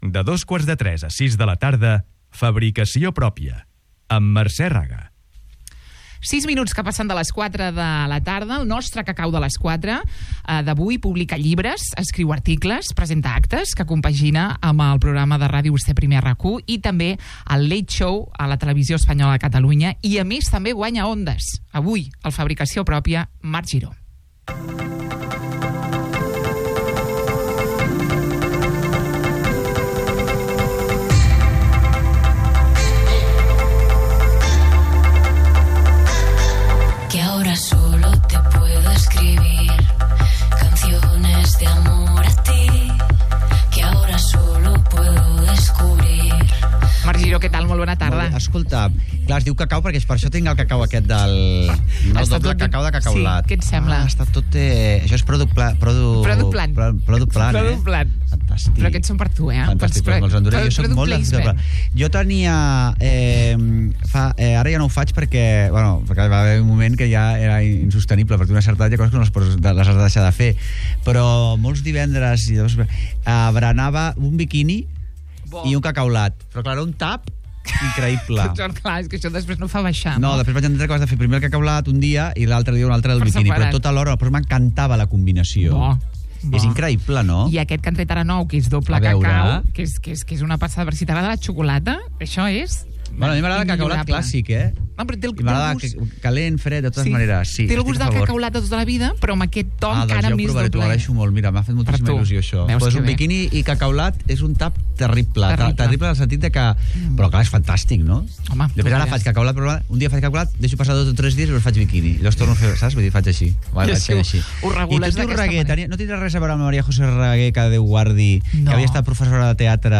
Marc Giró guanya el Premi Nacional de Comunicació 2023. El periodista rep la notícia mentre estava en directe a Ràdio Estel.
Entrevista a Marc Giró, Premi Nacional de Comunicació 2023